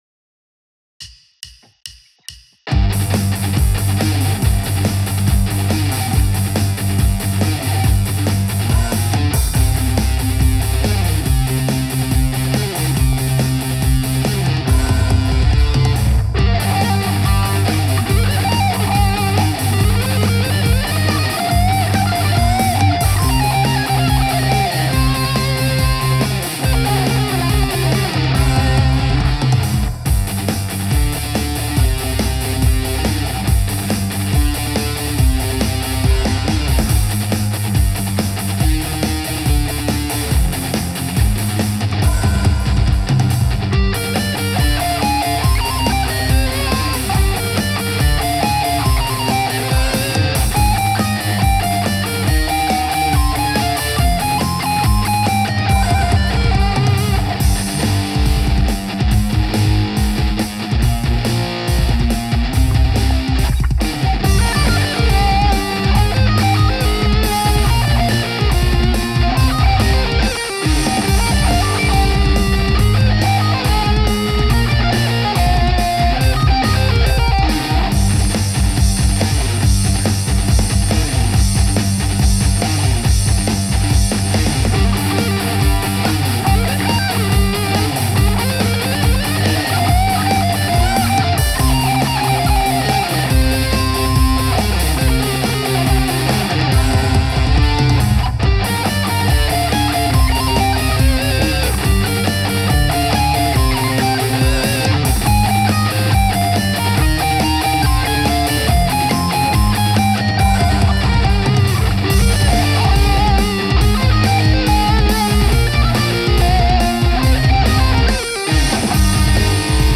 Only nit would be a bit lacking in bass guitar...
The guitar is great on the Em thing as well...cool shredding, enjoyed it!